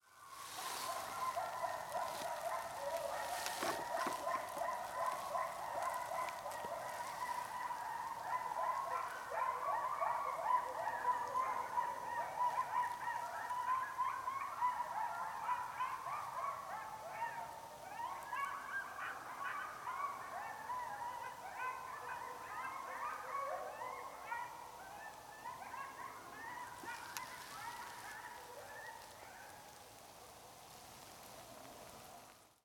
The deal was, I was on the side of a lonely road, but my framing was fairly low and so oncoming car headlights could actually illuminate the lens even when the road wasn’t in the frame.
Now, also while out there, some nearby canids decided to have an argument, and I quickly started recording video just to capture the audio, though this is only with the on-camera mic:
You’ll notice that everything is in the same general pitch, so unless there’s a puppy mill someplace out there (which I certainly won’t rule out in rural North Carolina,) I’d say I caught a dispute among a pack or two of coyotes (Canis latrans.)
Coyotes1125.mp3